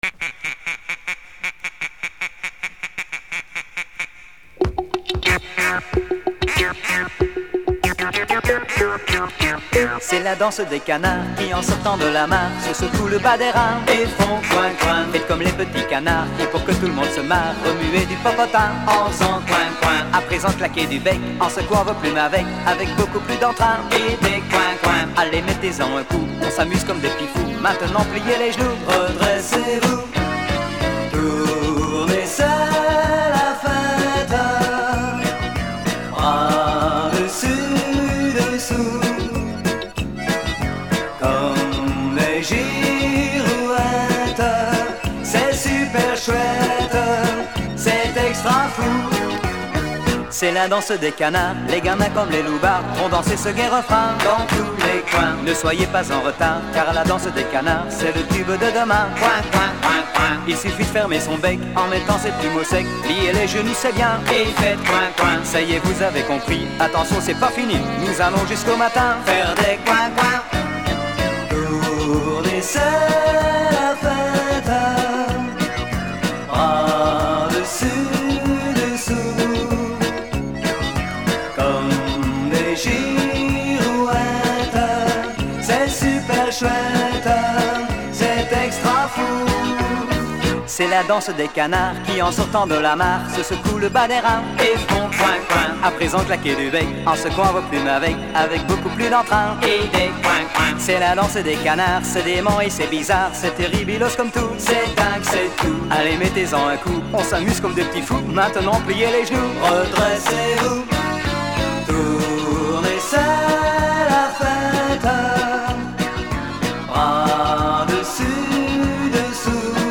Paroles et musique à écouter ou télécharger :